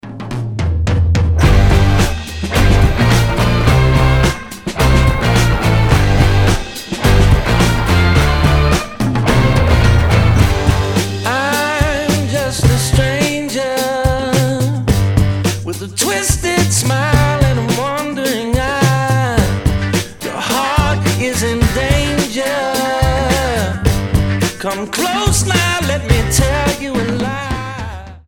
• Качество: 320, Stereo
гитара
indie rock
бодрые
барабаны
Блюз-рок